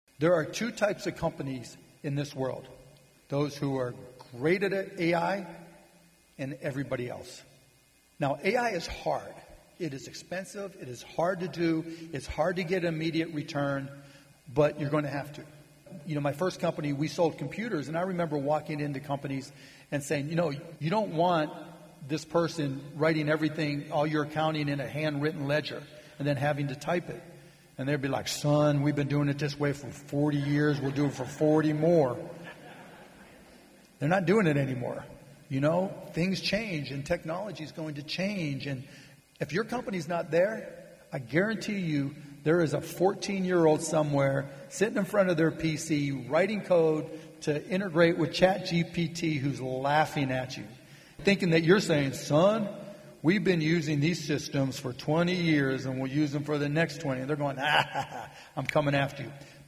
In Cuban’s eyes, artificial intelligence is the future. Hear more of Cuban's comments on AI during his talk at the Dallas Regional Chamber's annual meeting in January.